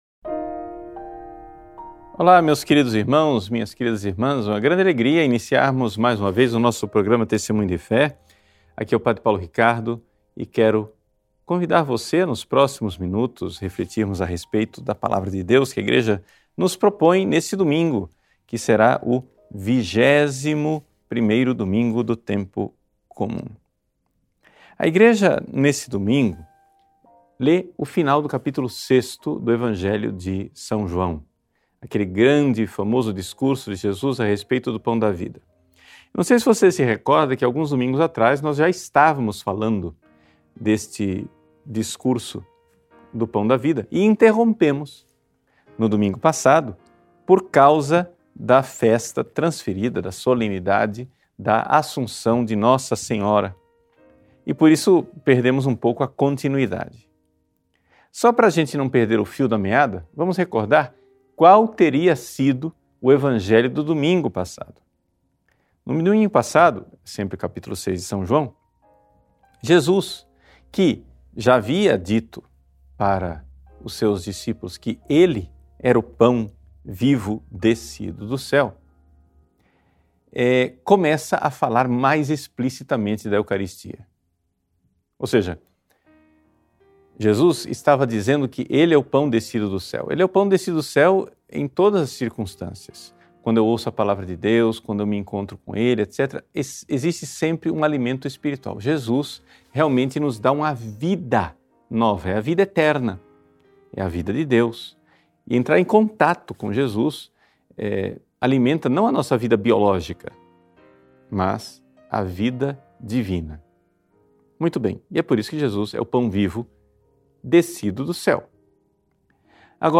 Material para Download Áudio da homilia (Formato .mp3) O que achou desse conteúdo?